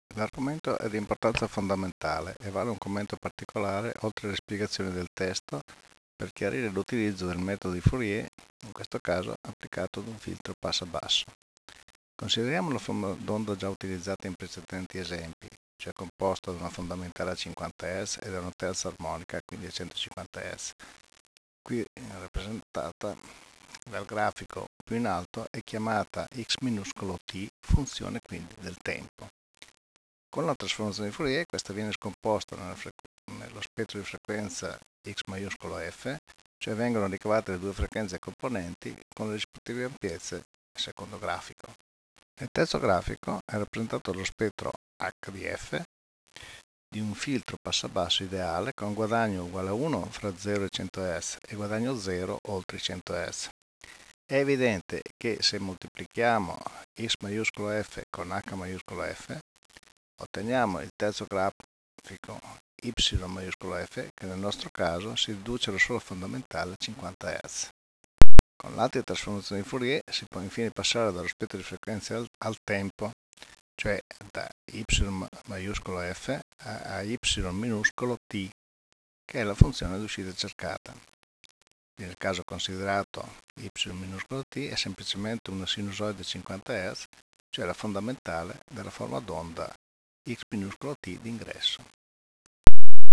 [commento audio]